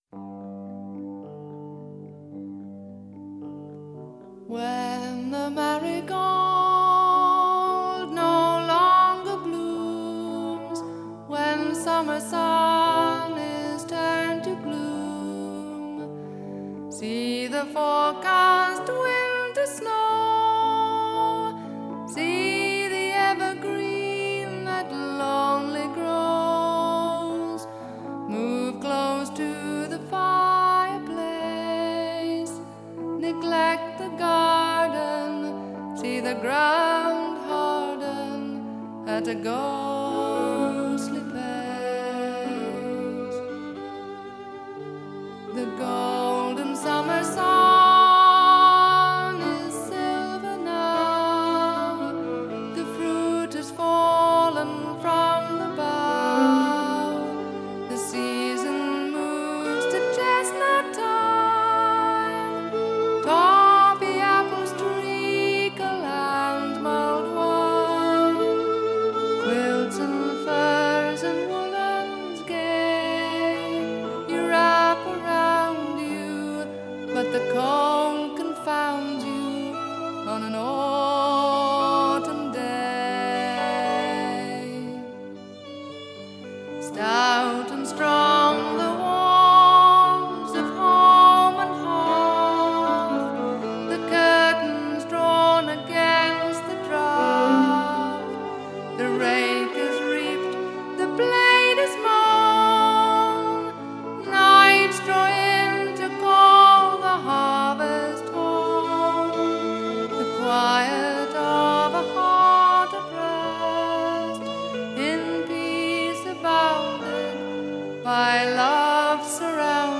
British folk rock